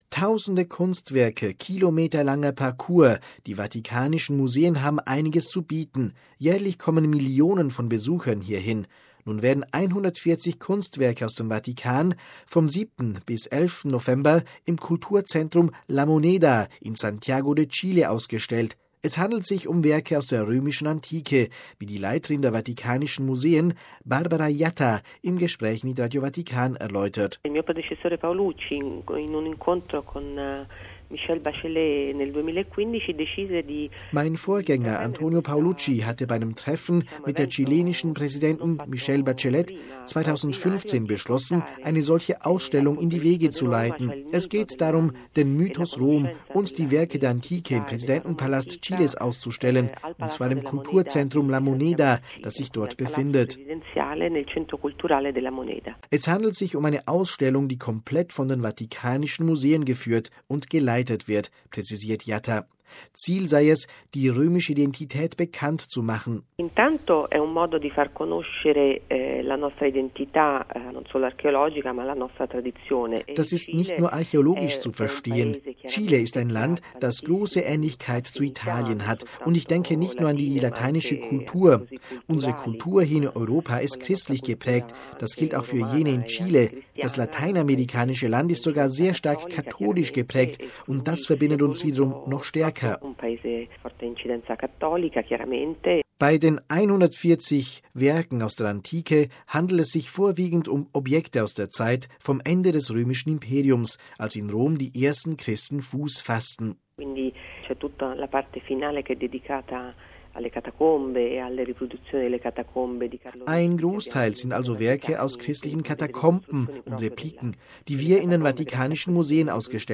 Nun werden 140 Kunstwerke aus dem Vatikan vom 7. November bis 11. März 2018 im Kulturzentrum La Moneda in Santiago de Chile ausgestellt. Es handelt sich um Werke aus der römischen Antike, wie die Leiterin der Vatikanischen Museen, Barbara Jatta, im Gespräch mit Radio Vatikan erläutert.